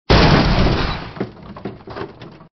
carimpact2.wav